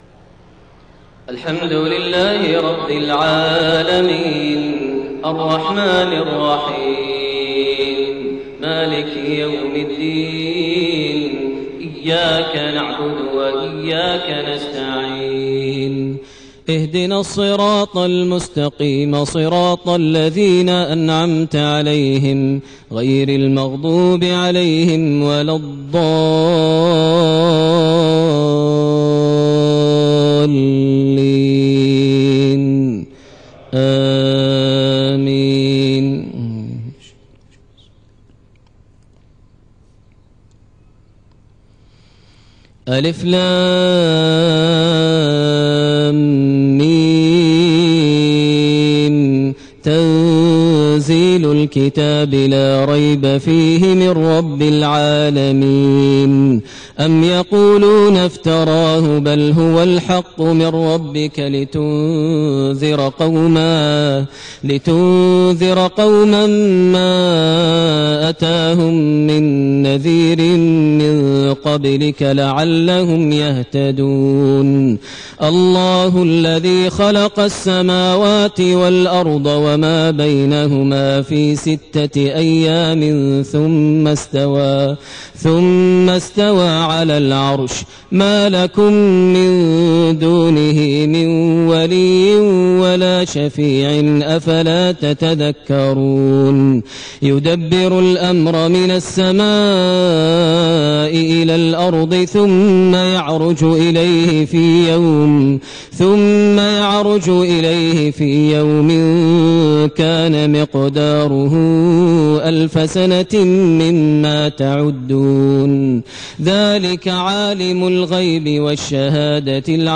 صلاة الفجر 5-4‐1429 سورتي السجدة والانسان > 1429 هـ > الفروض - تلاوات ماهر المعيقلي